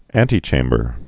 (ăntē-chāmbər)